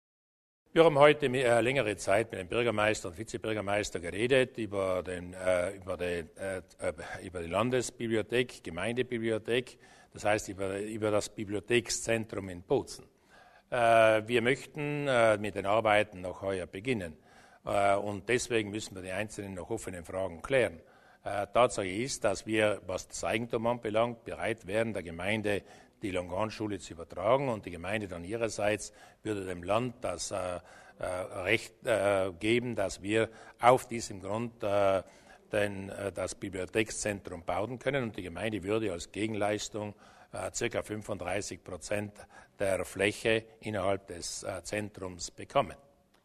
Landeshauptmann Durnwalder zum neuen Bibliothekenzentrum